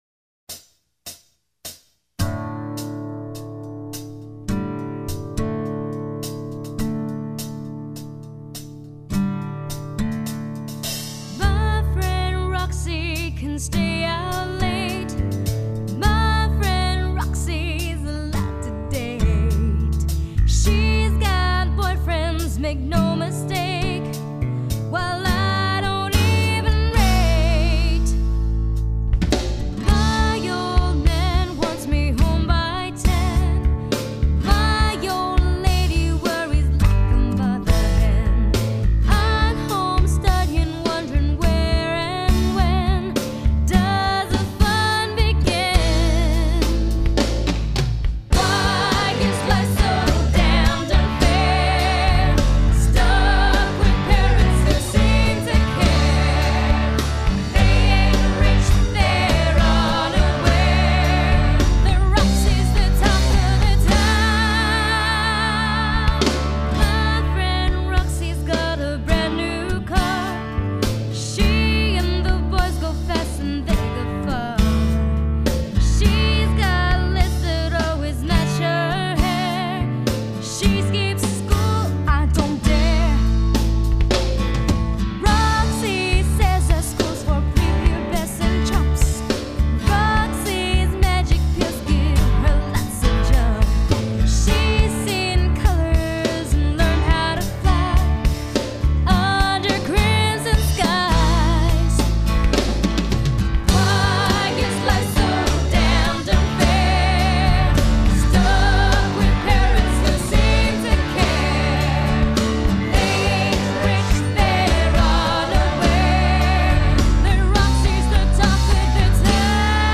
At age 16 she had the power of Tina Turner and the smoothness of Whitney Houston..Although originally commissioned to create computer-based tracks.the project had such great potential that I started replacing the MIDI tracks with real musicians.